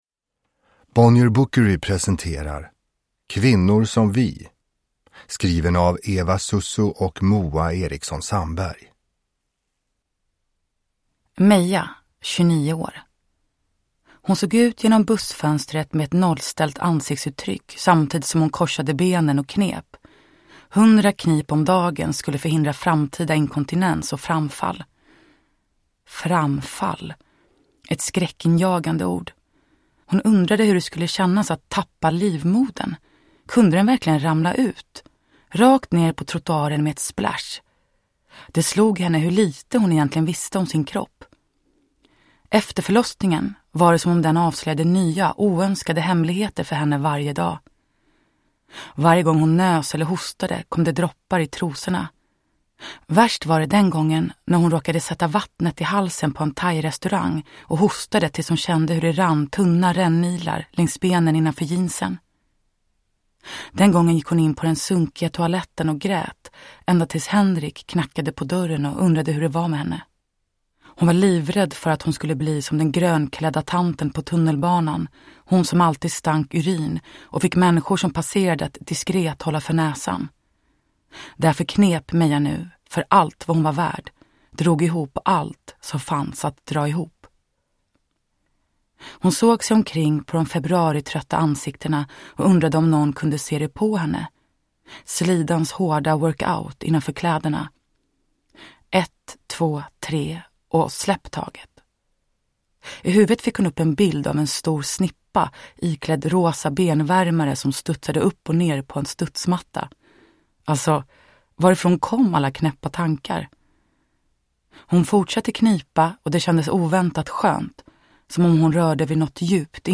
Uppläsare:
Moa Gammel
Anna Takanen
Maria Lundqvist
Ljudbok